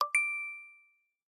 Звуки банковской карты
Звук бесконтактной оплаты в магазине через Apple Pay